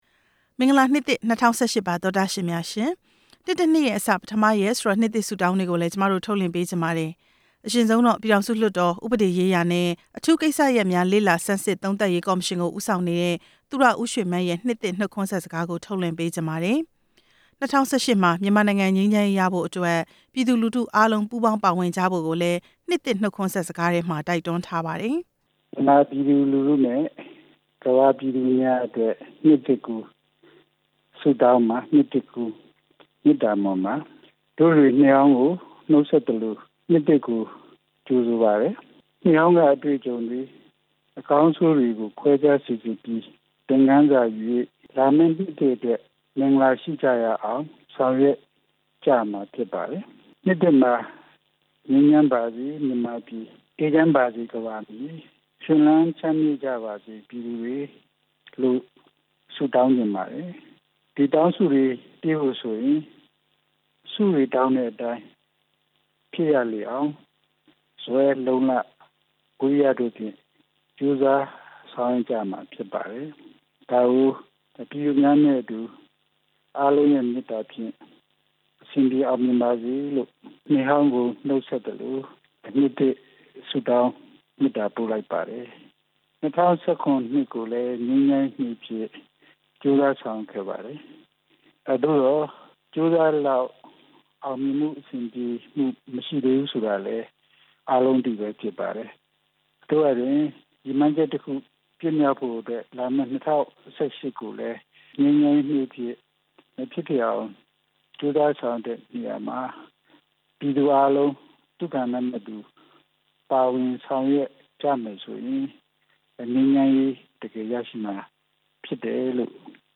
သူရ ဦးရွှေမန်းရဲ့ နှစ်သစ် နှုတ်ခွန်းဆက်စကား